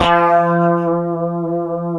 F#3 HSTRT VB.wav